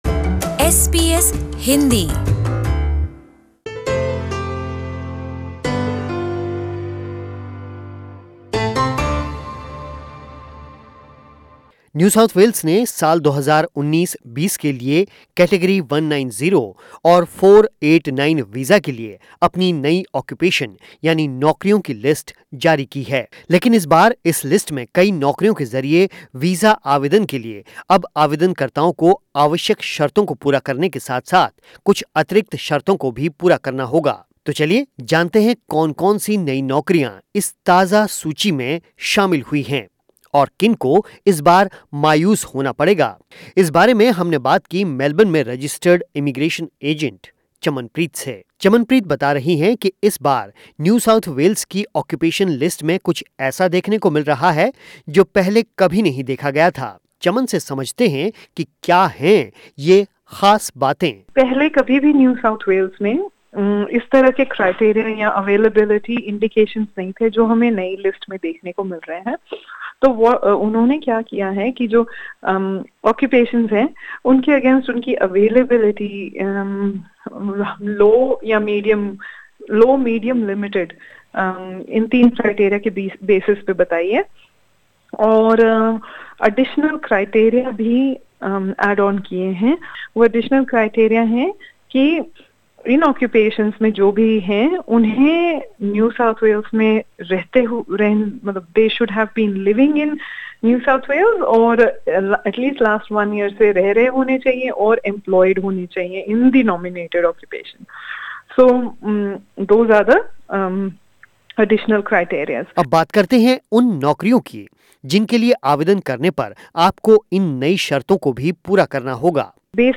सुनिए यह पूरी बातचीत...